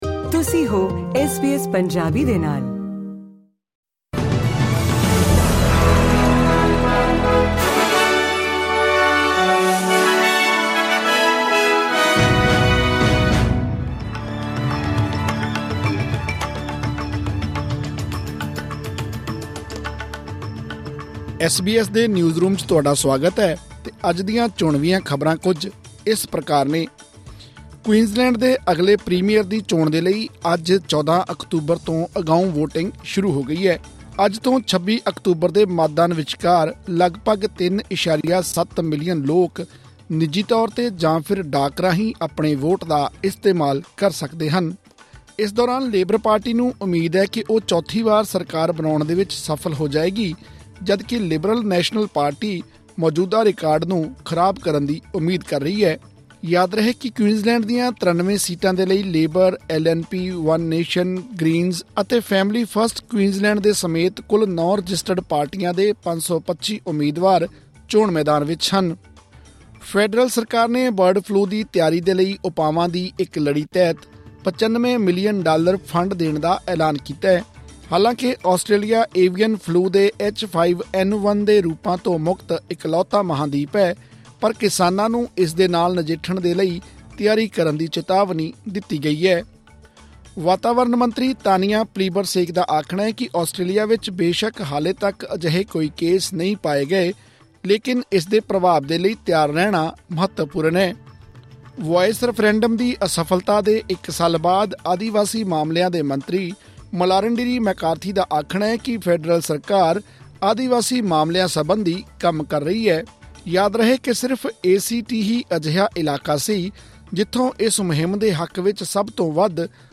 ਐਸ ਬੀ ਐਸ ਪੰਜਾਬੀ ਤੋਂ ਆਸਟ੍ਰੇਲੀਆ ਦੀਆਂ ਮੁੱਖ ਖ਼ਬਰਾਂ: 14 ਅਕਤੂਬਰ, 2024